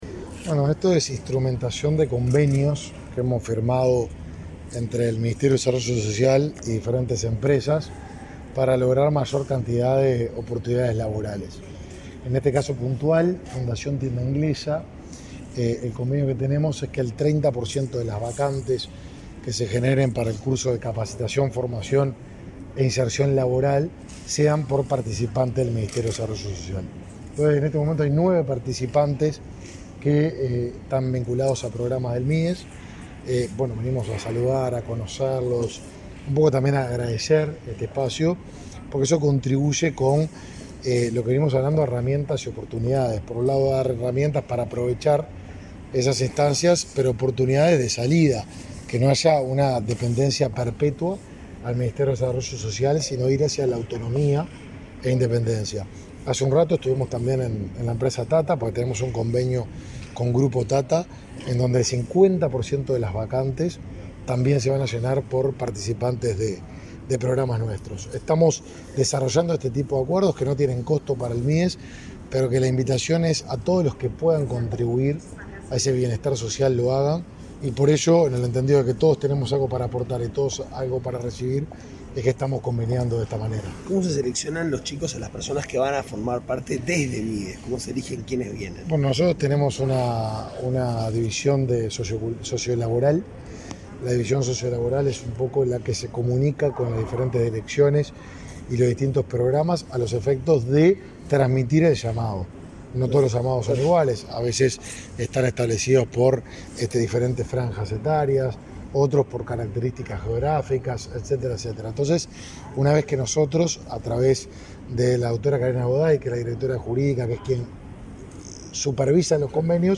Declaraciones a la prensa del ministro de Desarrollo Social, Martín Lema
Tras finalizar el recorrido por la empresa TA-TA y por los talleres de Tienda Inglesa para capacitar a los trabajadores beneficiarios de políticas del Ministerio de Desarrollo Social (Mides), Lema efectuó declaraciones a la prensa. Esa dependencia estatal mantiene convenio con empresas, para fomentar la contratación de personas beneficiarias de políticas sociales.